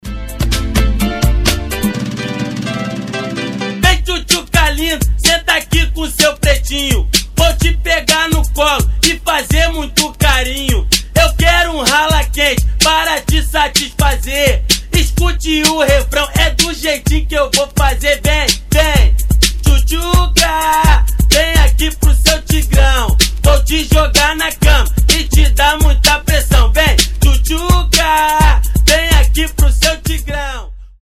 • Качество: 320, Stereo
Бразильские
качающие
смешные
hip house